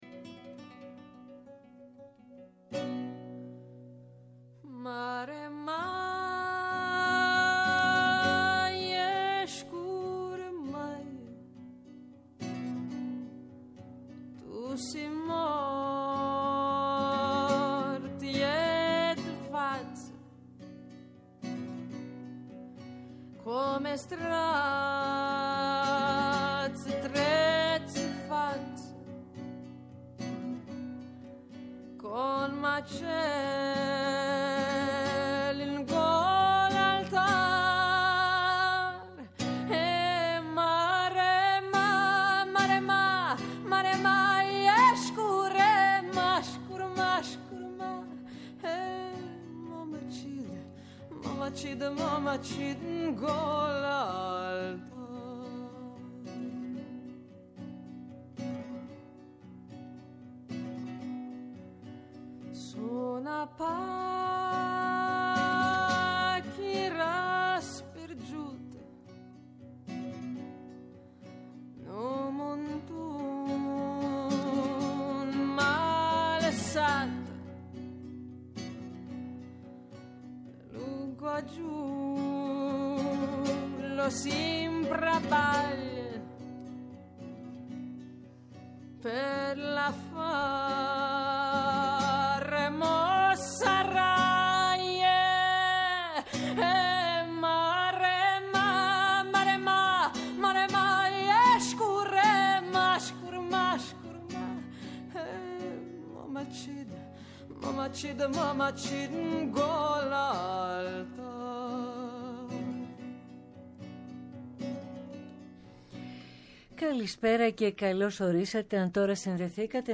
Μια συζήτηση με τον μετρ της ελληνικής αστυνομικής λογοτεχνίας.